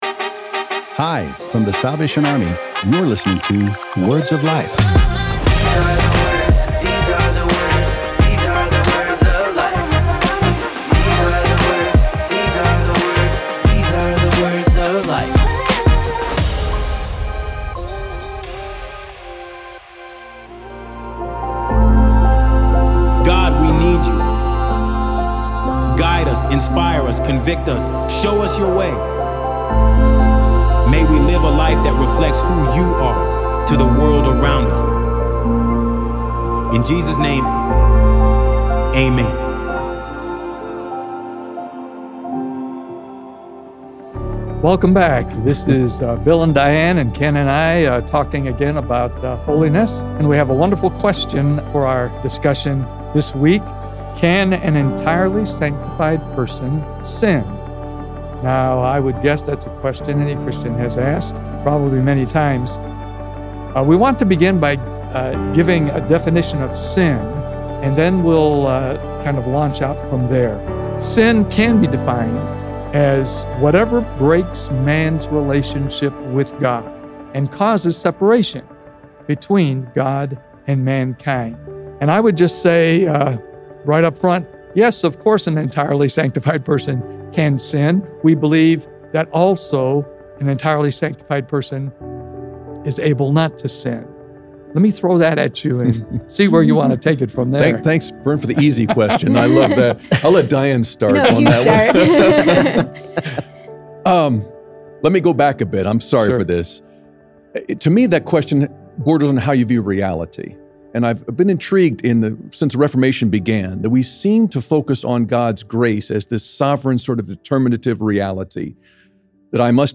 As we continue this group conversation about holiness, today the panel discusses a very common question.